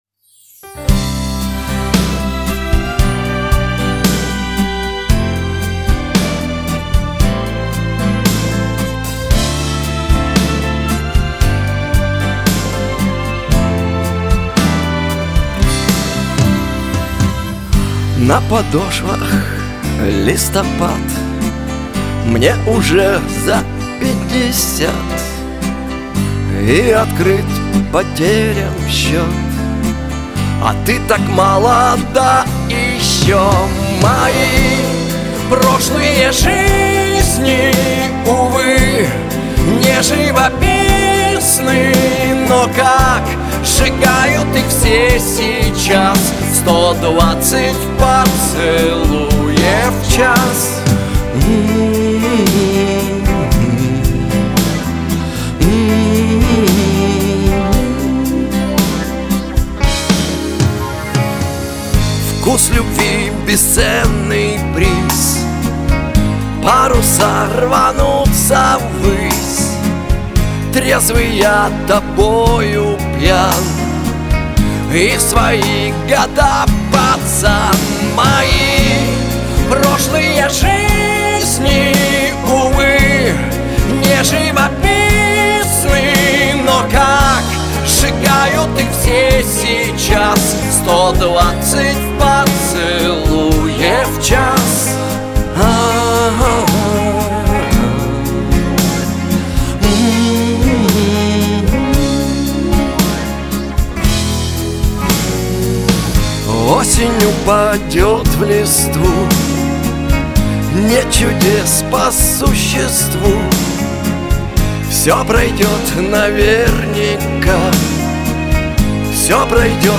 Теги: pop , Шансон , эстрада , Лирика